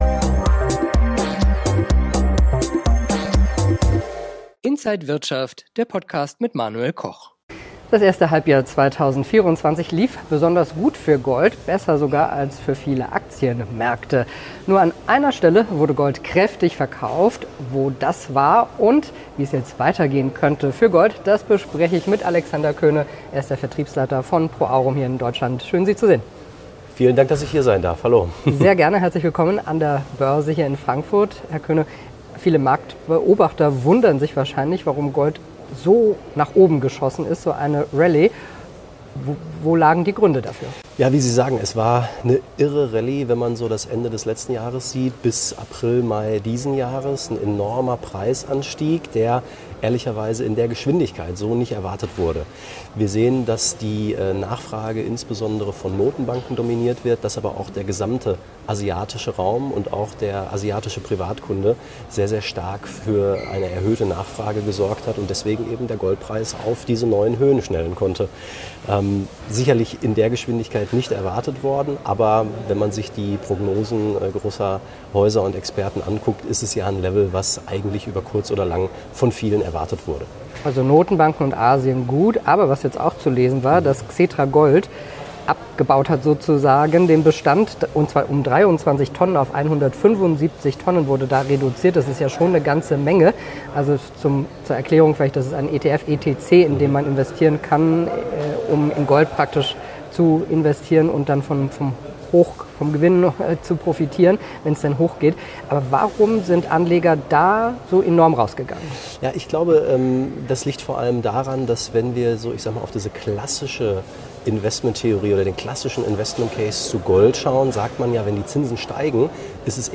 Alle Details im Interview